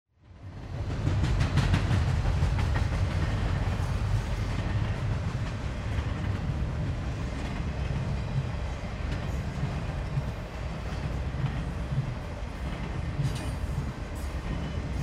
Train Passing Long version 2
Transportation Sound Effects